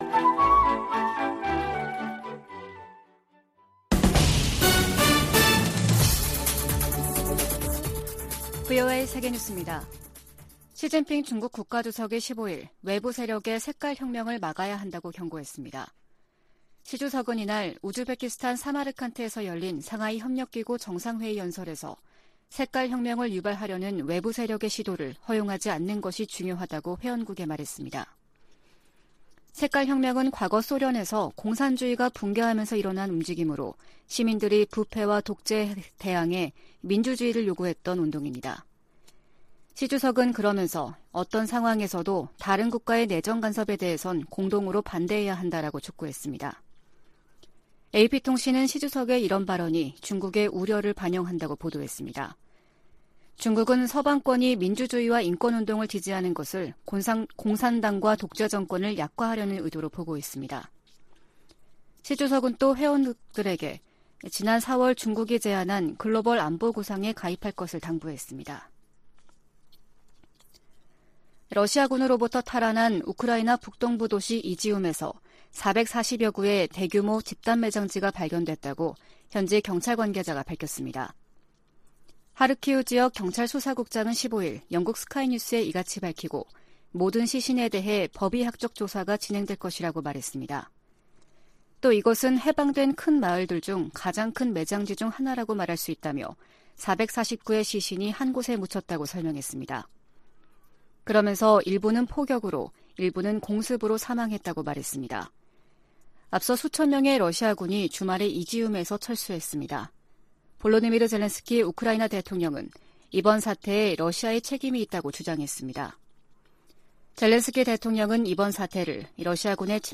VOA 한국어 아침 뉴스 프로그램 '워싱턴 뉴스 광장' 2022년 9월 17일 방송입니다. 리잔수 중국 전국인민대표대회 상무위원장은 북핵 문제에 관해 한반도 평화체제 구축이 당사국들의 이익에 부합한다는 원칙을 거듭 밝혔습니다. 미 국무부는 미한 확장억제전략협의체 회의에서 북한 위협에 맞서 협력을 확대하는 방안이 논의될 것이라고 밝혔습니다. 미국 전략사령관 지명자가 북한 핵 미사일 위협에 맞선 차세대 요격 미사일(NGI) 개발을 지지한다고 말했습니다.